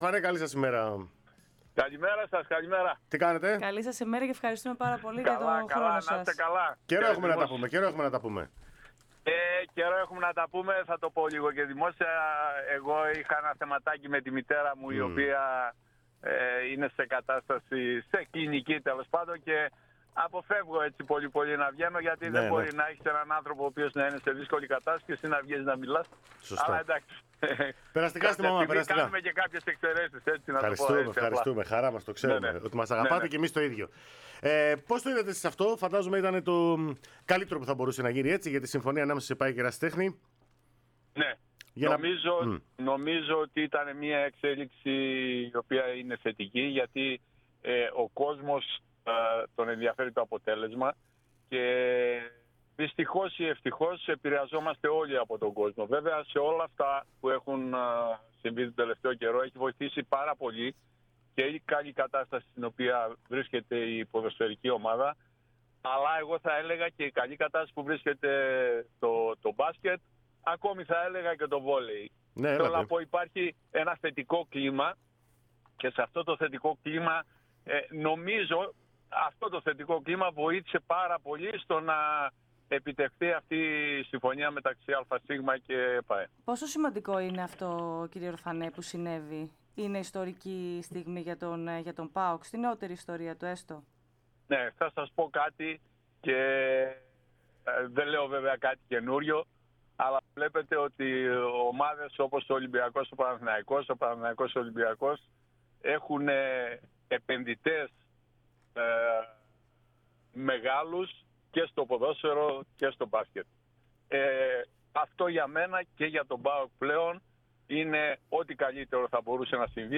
στον αέρα της ΕΡΑ ΣΠΟΡ